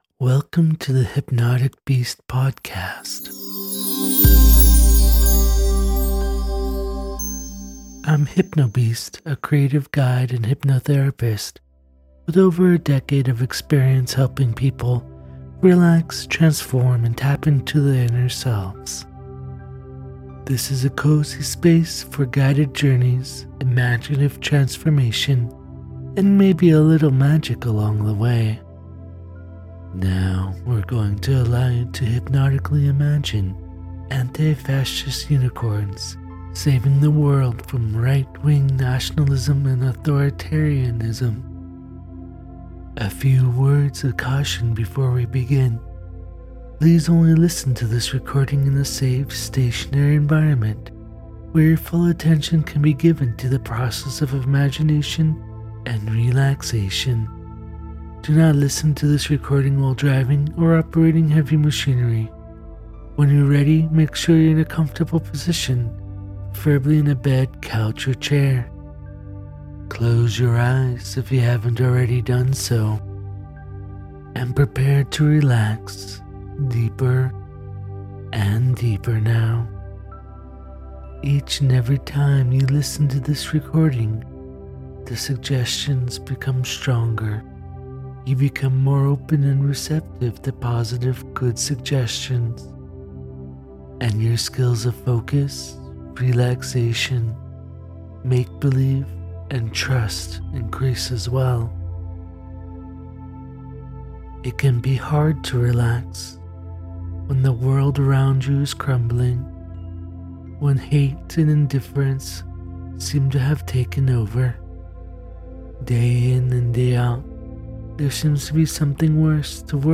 So here’s another silly political guided imagery journey featuring Antifa Unicorns. It should be a mix of hopeful and humorous.